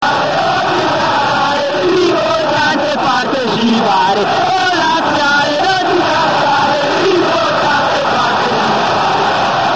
Roma, Stadio Olimpico,